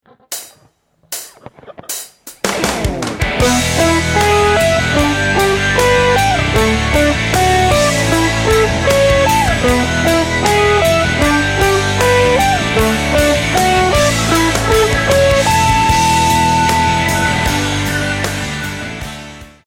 In this guitar lesson the CAGED Chord System will be used over a 4 chord progression showing ideas to create a guitar solo based on arpeggios.
The chords in the example are E G D and A major which are the same chords used in the chorus of Alive by Pearl Jam and countless other songs.
Finally there’s the G major chord shape again using the same 4 strings of the shape as it is moved around the guitar neck.
CAGED G Chord Shape Arpeggios